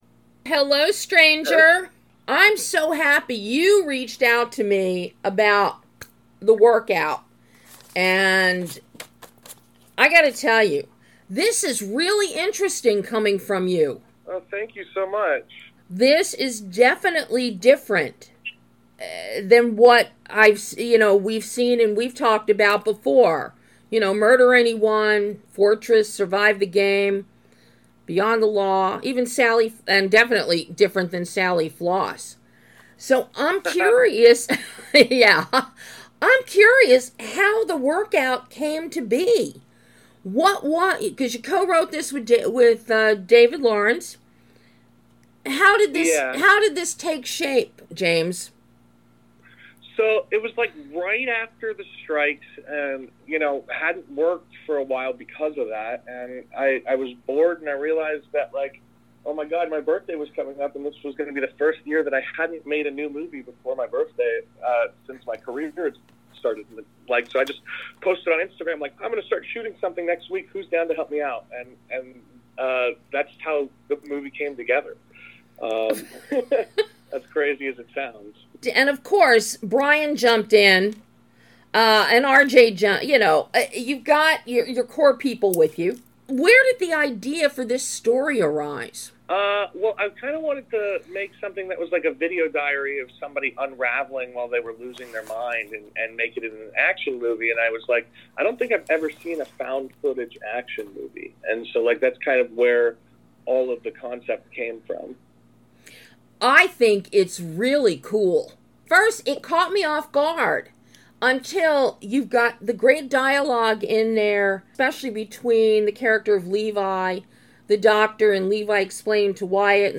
THE WORKOUT - Exclusive Interview